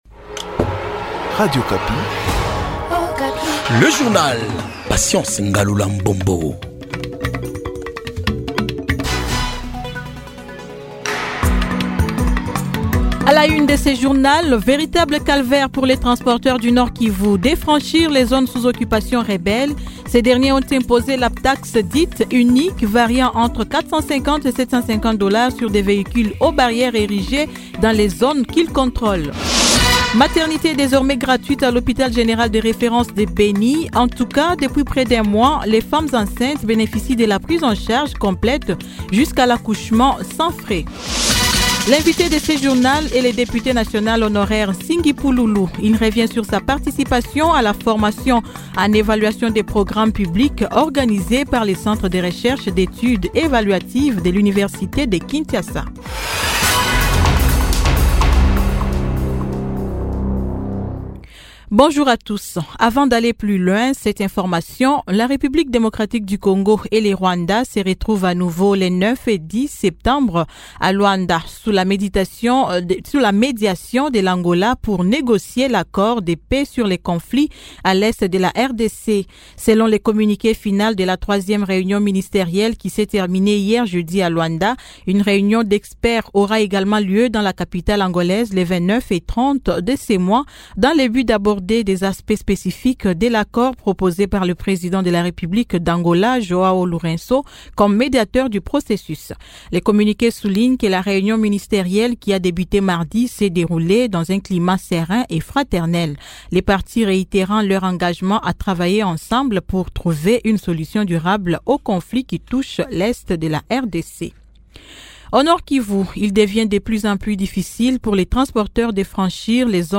Journal matin 08H